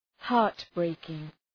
Προφορά
{‘hɑ:rt,breıkıŋ}